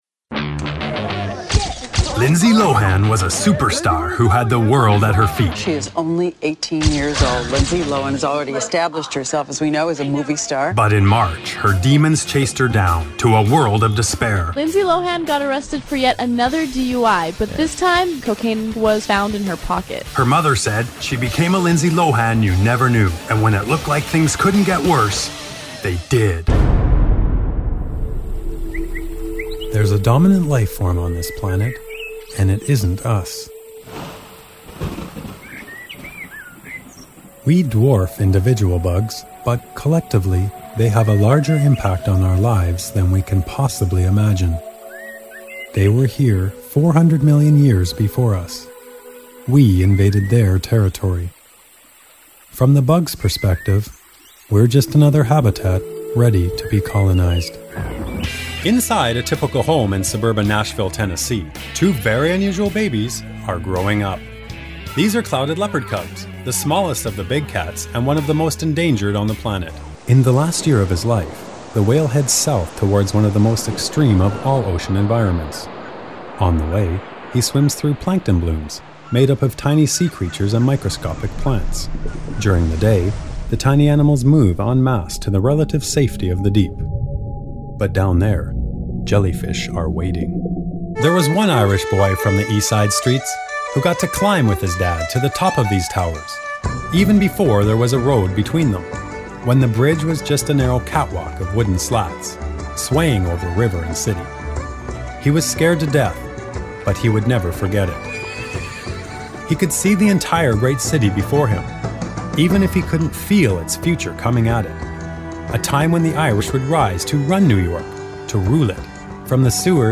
英語/北米・オセアニア地方 男性
CM Demo
Narration Demo